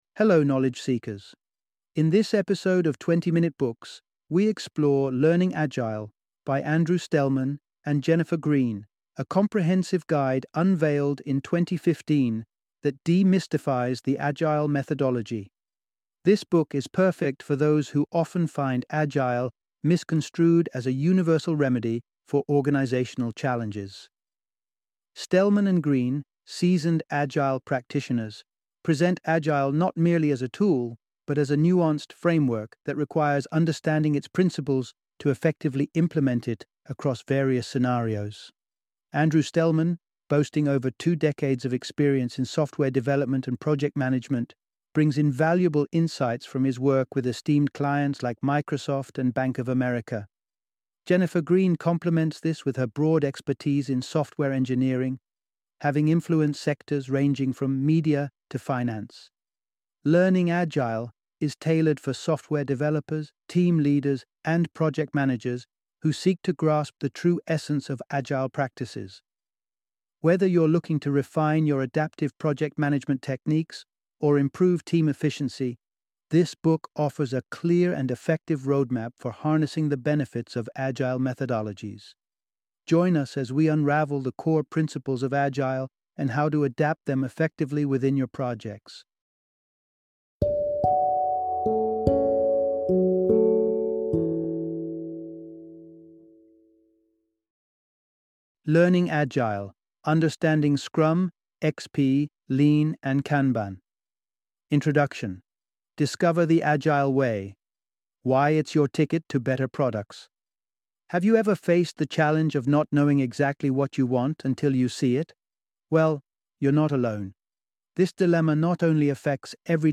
Learning Agile - Audiobook Summary